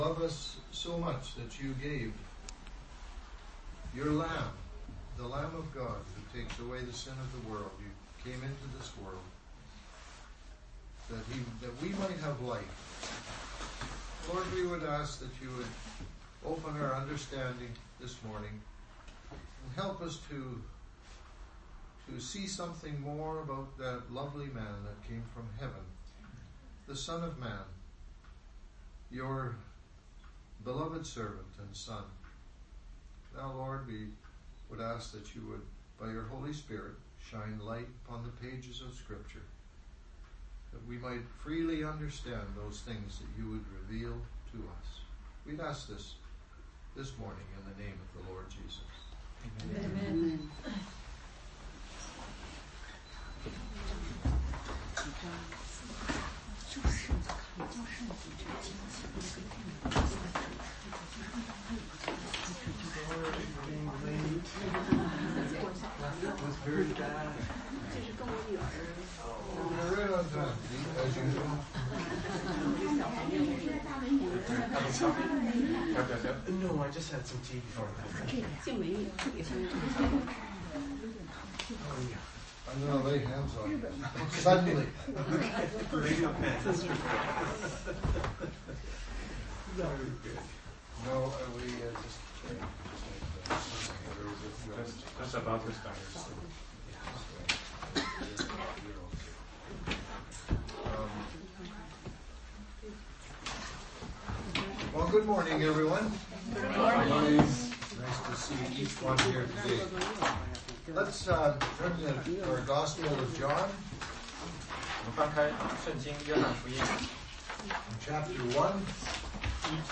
16街讲道录音 - 约翰福音1章35－42